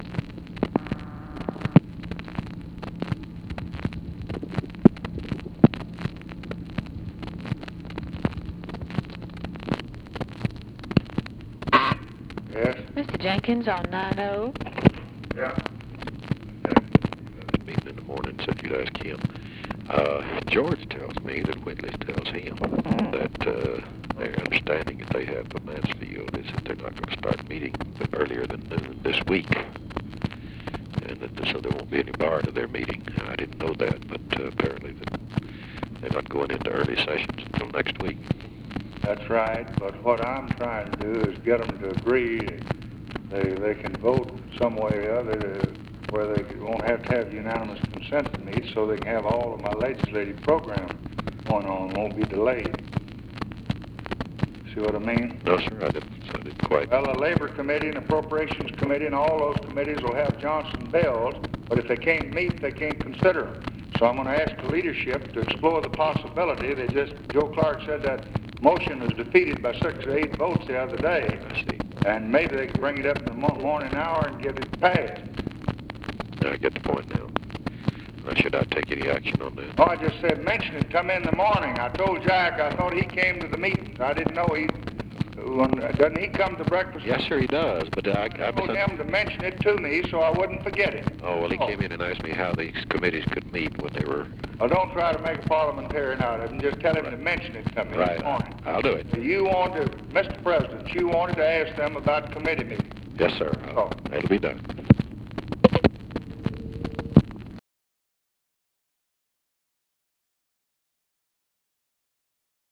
Conversation with WALTER JENKINS, March 9, 1964
Secret White House Tapes